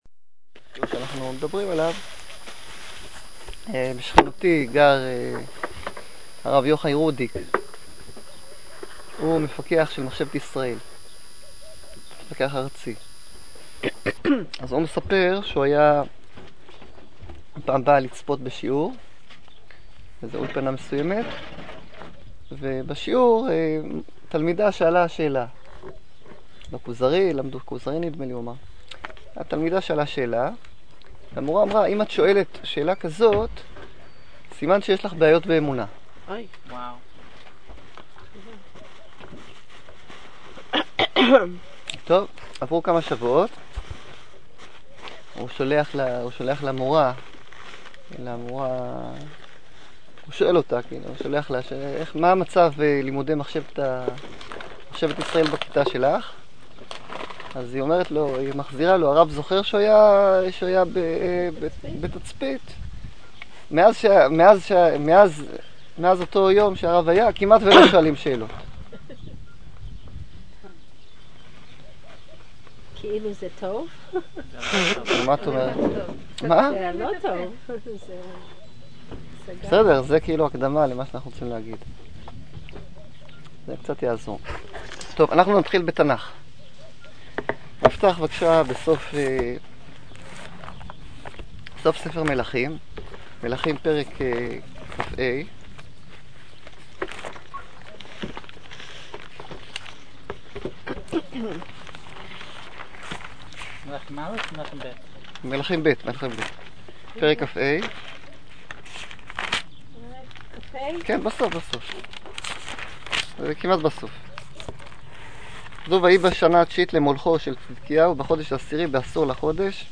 Torah lessons in Beit Hogla east of Jericho שיעורי תורה בבית חגלה על פני יריחו
כנס על בקעת יריחו - לרגל 11 שנים לבית חגלה